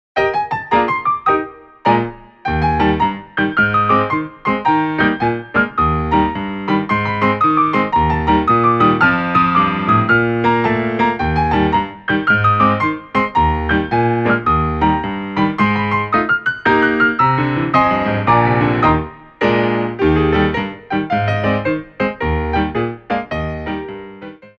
33 Inspirational Ballet Class Tracks
Glissés
6/8 (16x8)